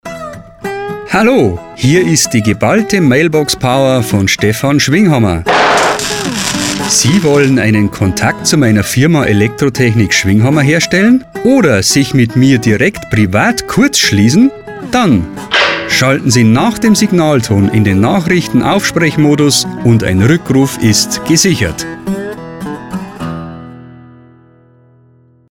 Mailboxansage
Elektrotechnik-Schwinghammer-Mailboxansage.mp3